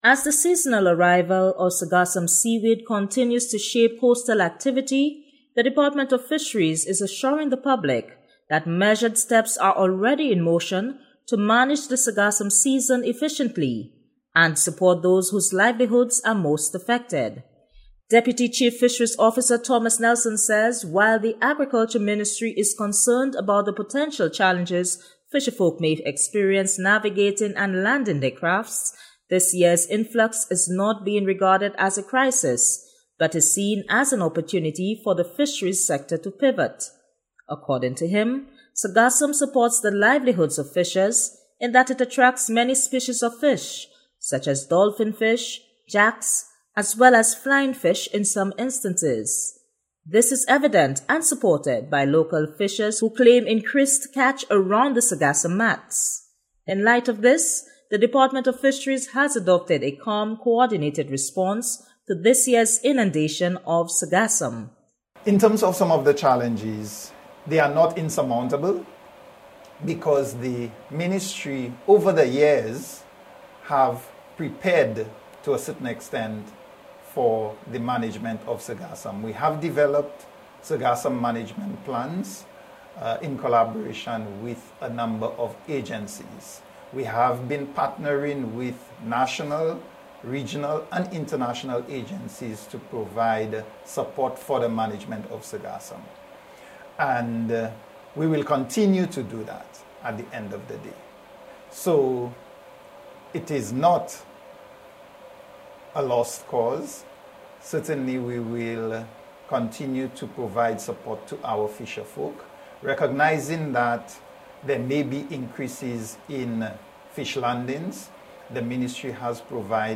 sargassum-report.ogg